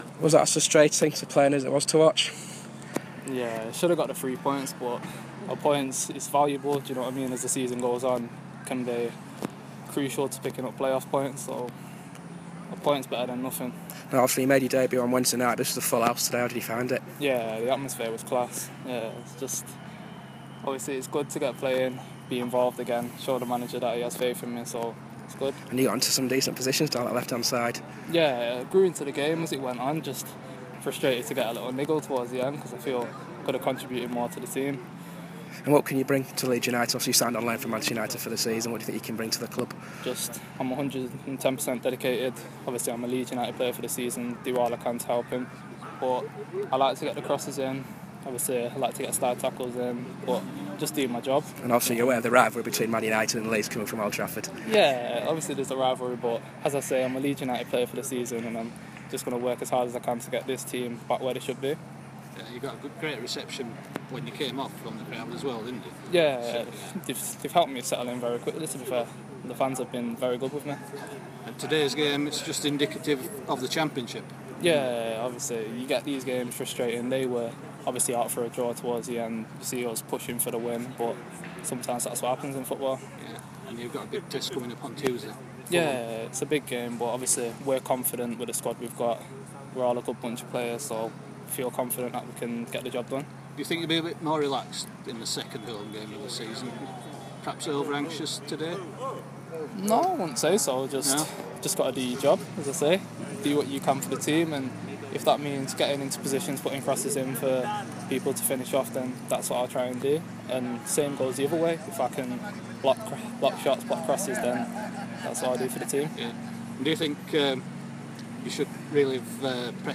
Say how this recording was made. The on-loan Manchester United man spoke to the assembled press following the 0-0 draw at Elland Road